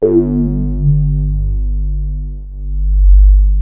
JUP 8 G2 6.wav